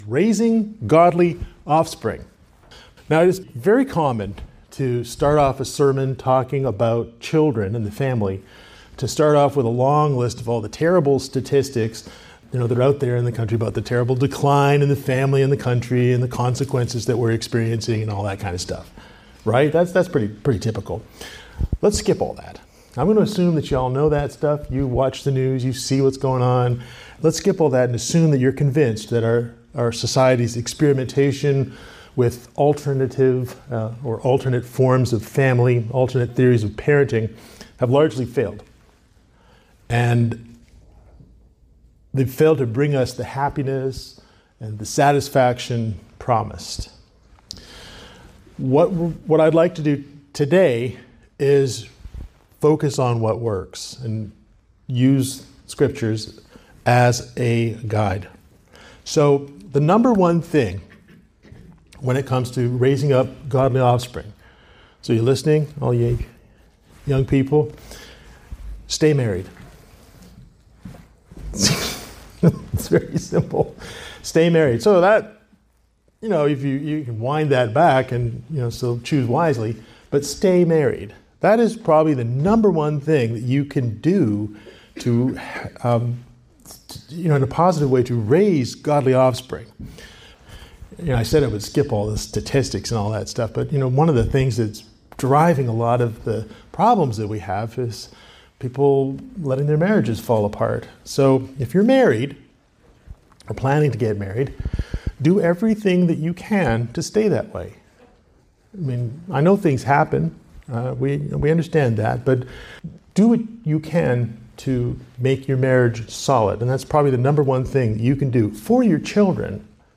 Its common to begin a sermon about children and the family with a long list of terrible statistics about the decline of the family in the country and the terrible consequences we are experiencing.